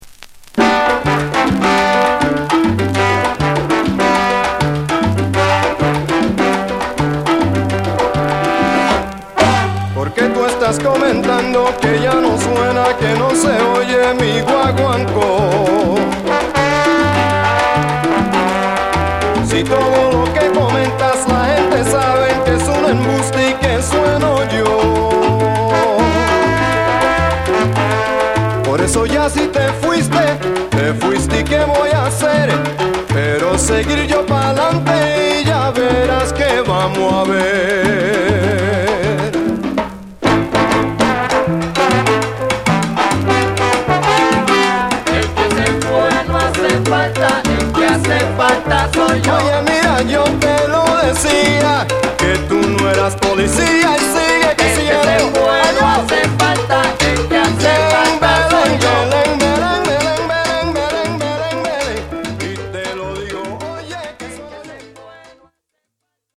陽気な掛け声やコーラス、演奏も素晴らしいです。